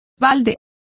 Complete with pronunciation of the translation of pails.